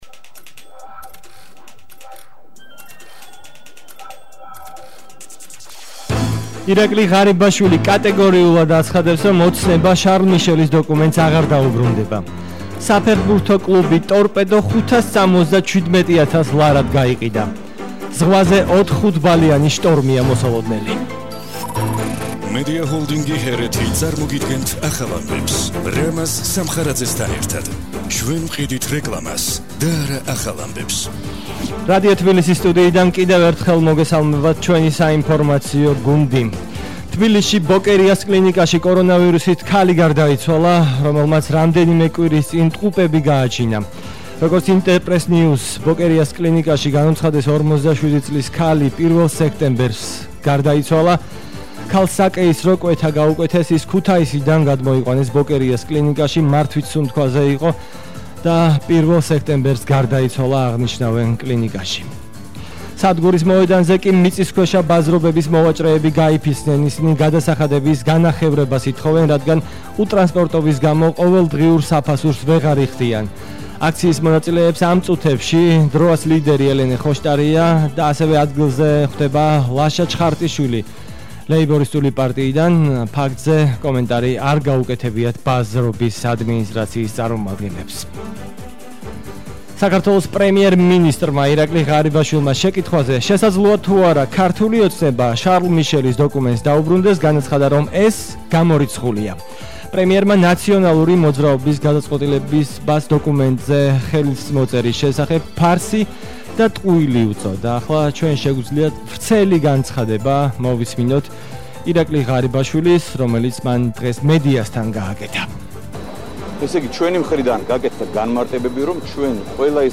ახალი ამბები 16:00 საათზე –2/09/21 - HeretiFM